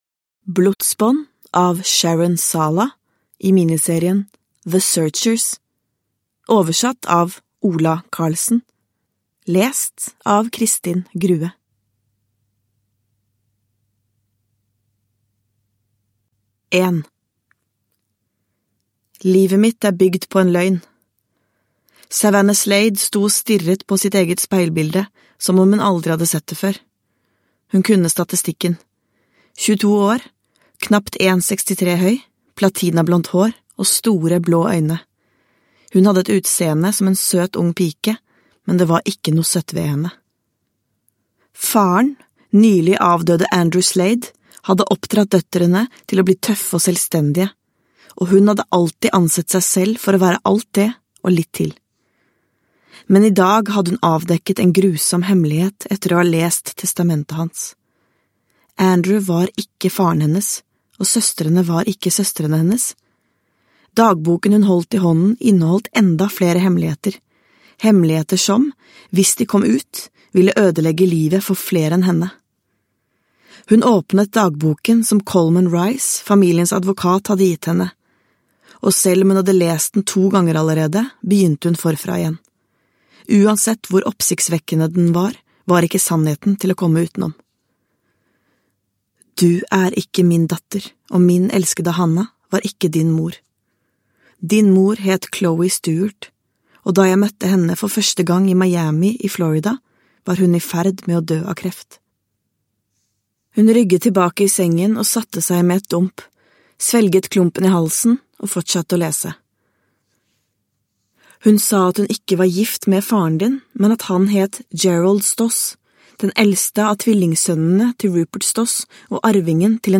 Blodsbånd – Ljudbok – Laddas ner
Uppläsare